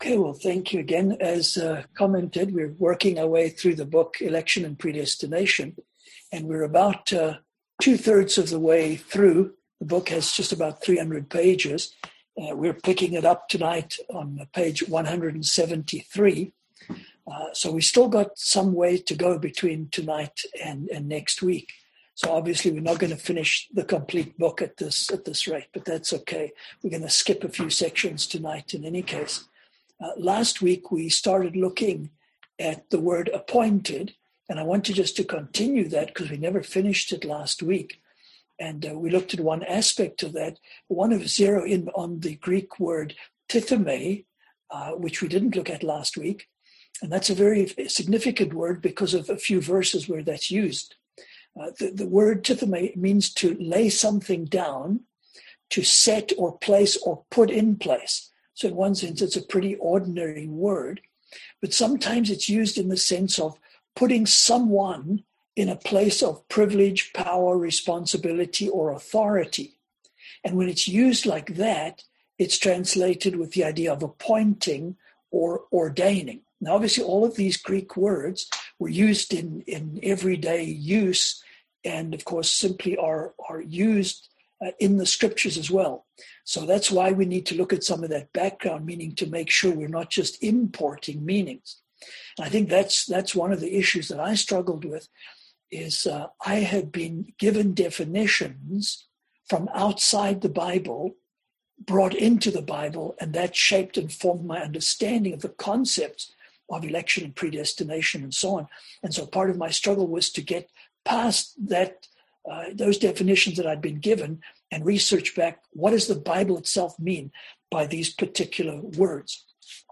Service Type: Seminar Topics: Election , Predestination